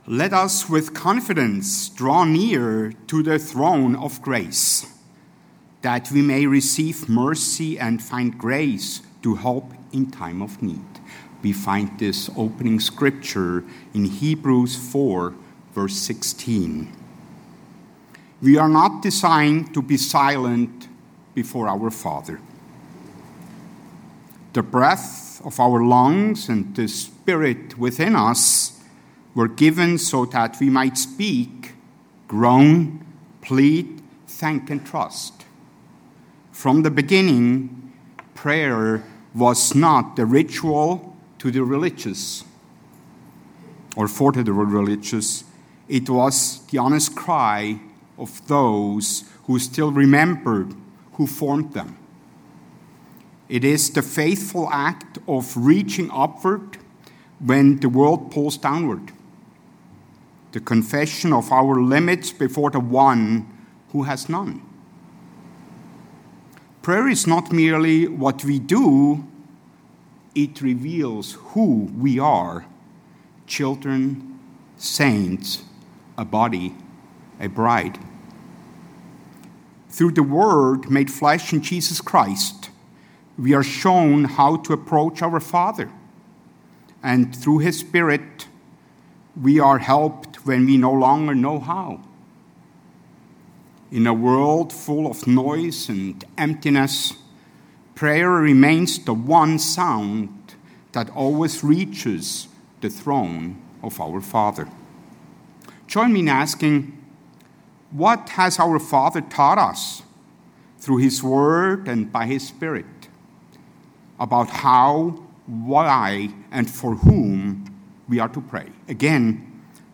Through scriptural clarity and spiritual urgency, this sermon calls us to return to the foundation of prayer as our identity, responsibility, and lifeline.